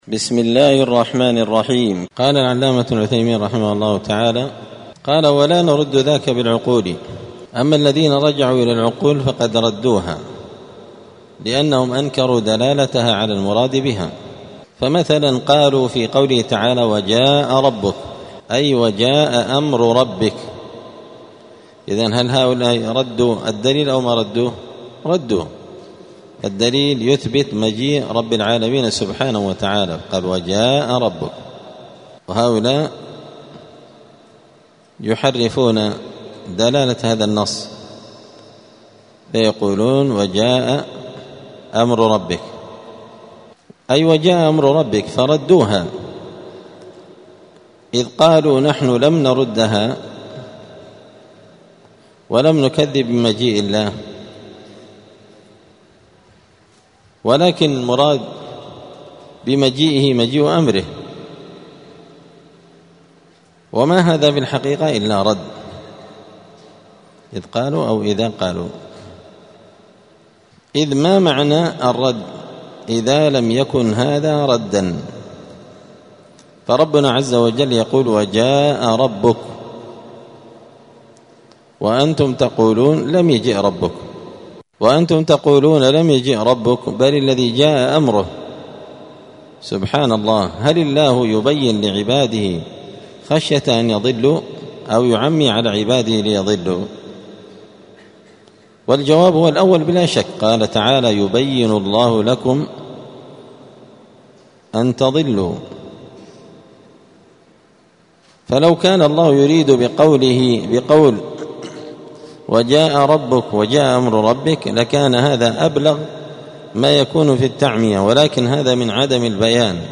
دار الحديث السلفية بمسجد الفرقان قشن المهرة اليمن
24الدرس-الرابع-والعشرون-من-شرح-العقيدة-السفارينية.mp3